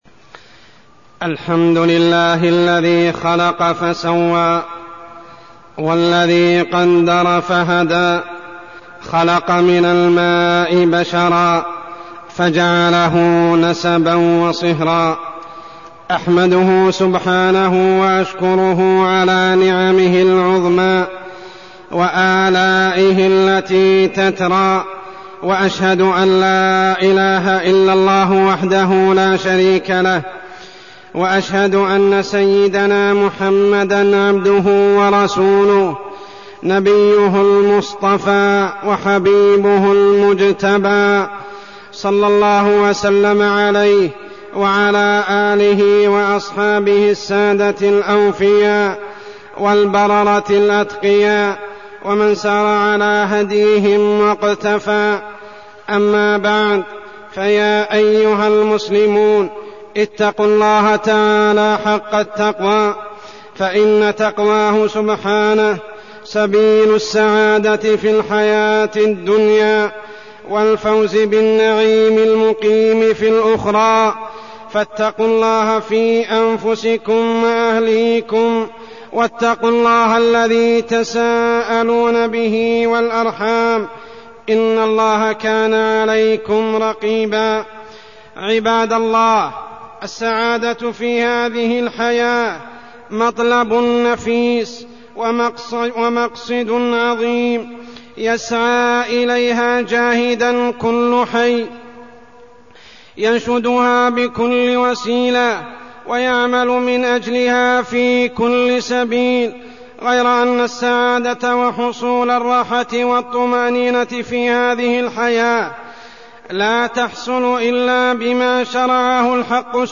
تاريخ النشر ٤ ربيع الأول ١٤١٧ هـ المكان: المسجد الحرام الشيخ: عمر السبيل عمر السبيل السعادة الزوجية